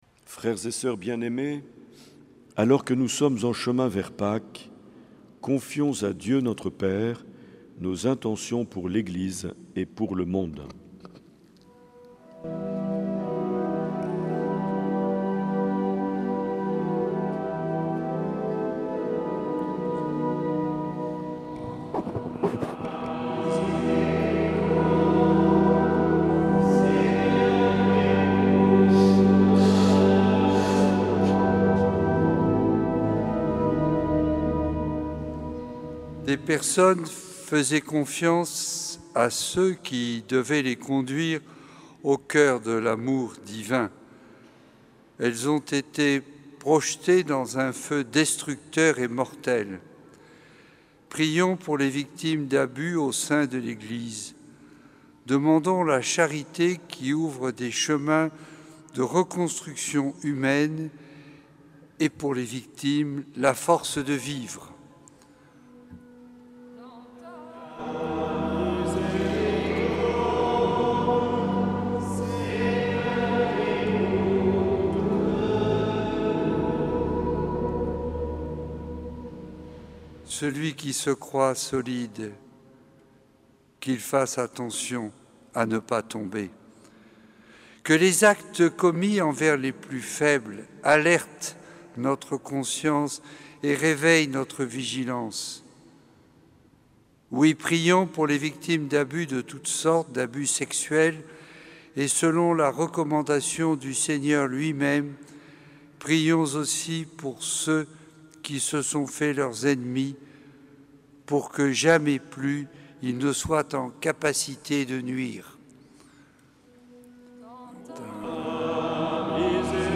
Prière universelle enregistrée en la cathédrale sainte Marie de Bayonne.